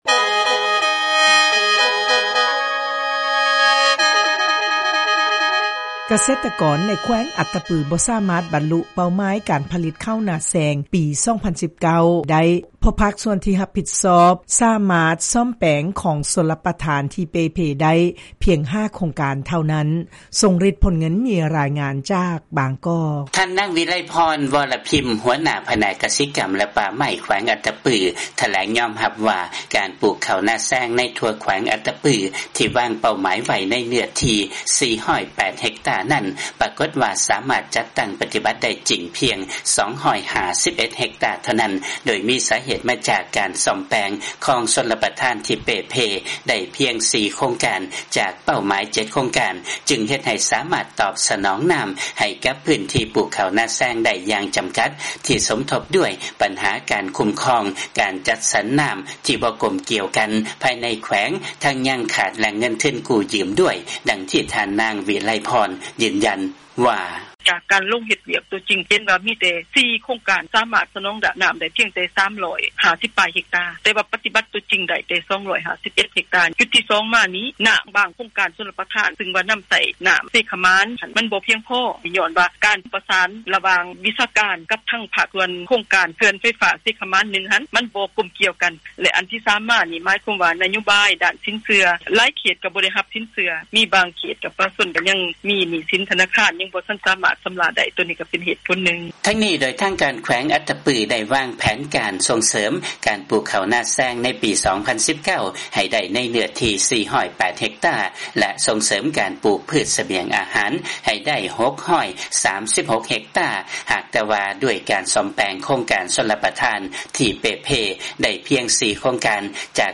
ຟັງລາຍງານ ກະເສດຕະກອນໃນແຂວງ ອັດຕະປື ບໍ່ສາມາດ ບັນລຸເປົ້າໝາຍ ການຜະລິດ ເຂົ້ານາແຊງໃນປີ 2019